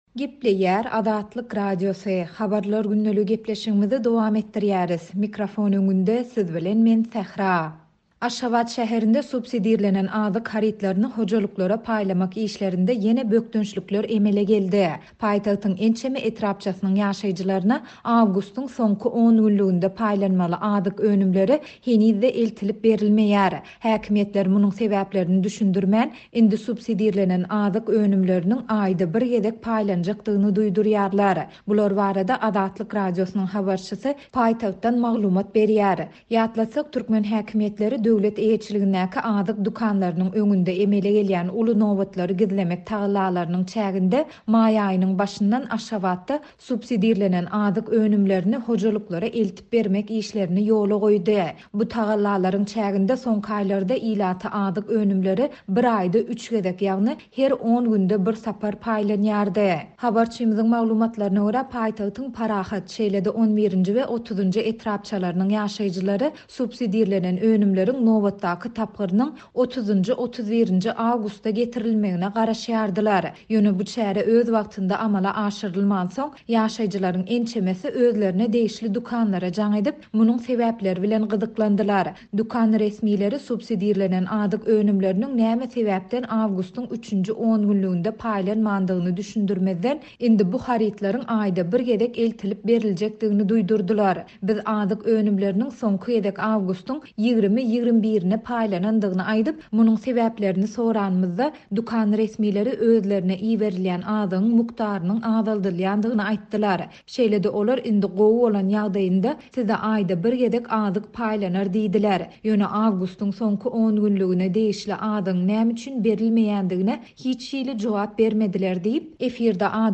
Bular barada Azatlyk Radiosynyň habarçysy paýtagtdan maglumat berýär.